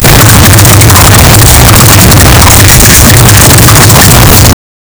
developer typing on his laptop clic commands
developer-typing-on-his-l-pzcbo2ho.wav